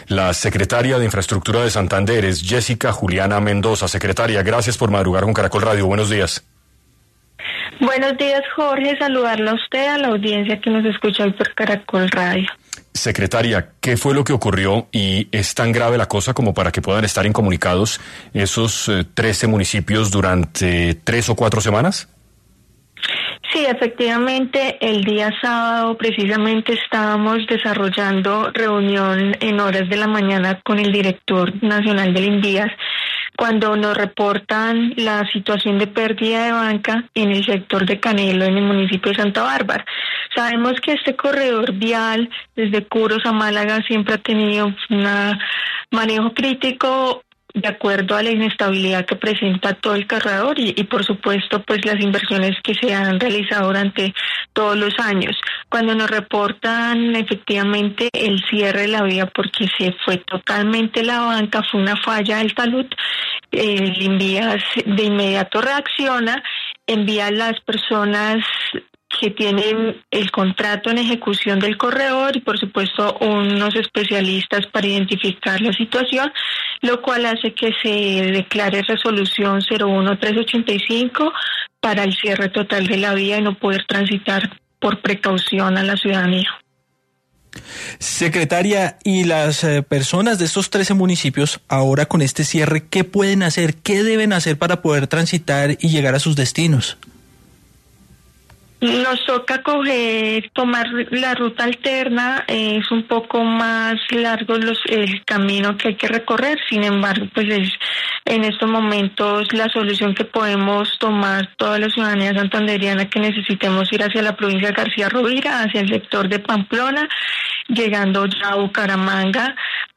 En entrevista para 6AM, Jessica Juliana Mendoza, Secretaria de Infraestructura de Santander, manifestó la problemática que podría presentarse en el departamento por las afectaciones que han dejado las lluvias a las vías.